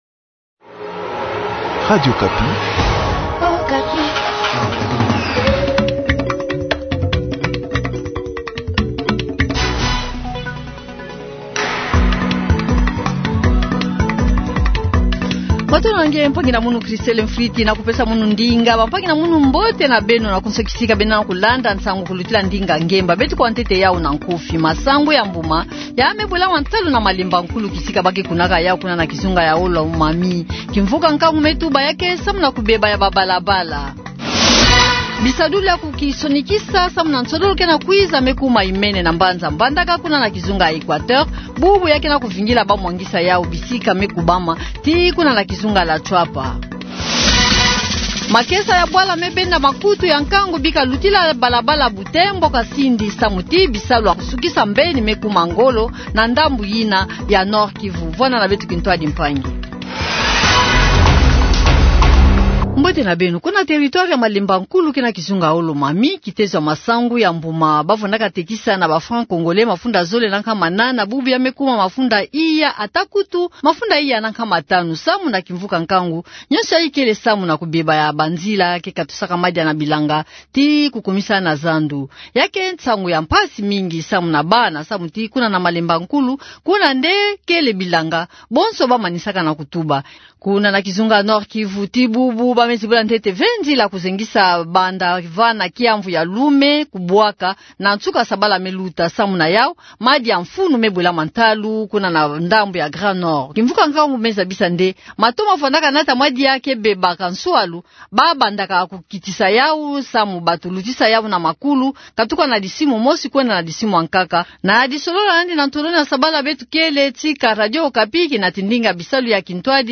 Journal du soir
• Washington : vox pop des congolais sur le sommet